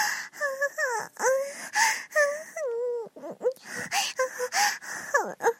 SFX女生娇喘累了音效下载
这是一个免费素材，欢迎下载；音效素材为女生娇喘音效累了， 格式为 mp3，大小1 MB，源文件无水印干扰，欢迎使用国外素材网。